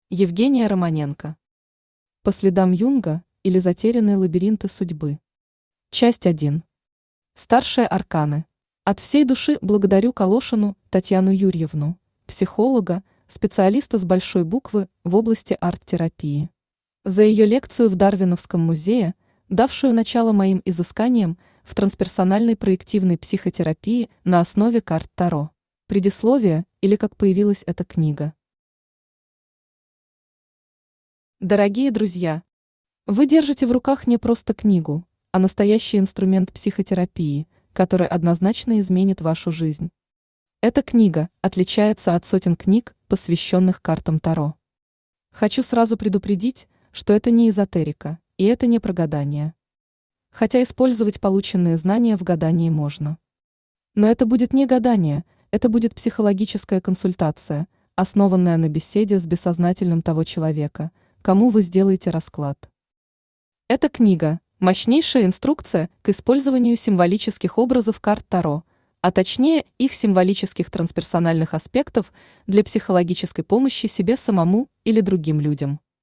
Аудиокнига По следам Юнга, или Затерянные лабиринты судьбы. Часть 1. Старшие арканы | Библиотека аудиокниг
Старшие арканы Автор Евгения Романенко Читает аудиокнигу Авточтец ЛитРес.